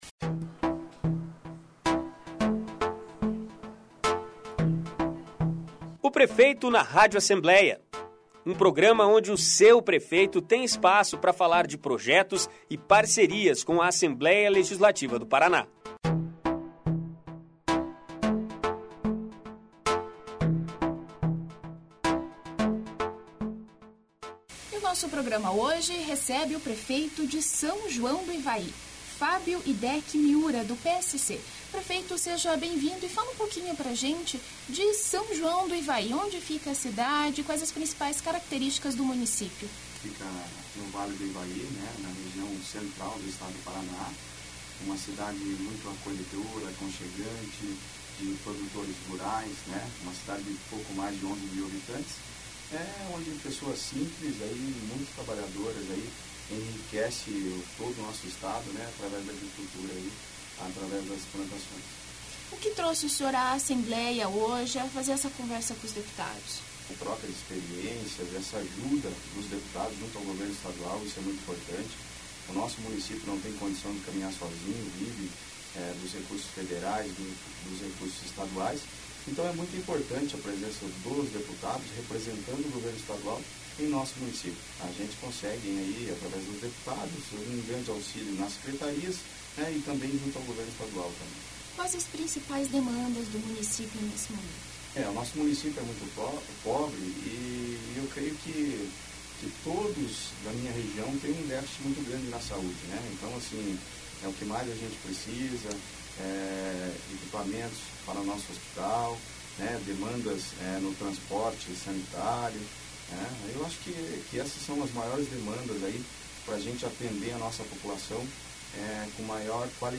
Prefeito de São João do Ivaí está no "Prefeito na Rádio Alep" esta semana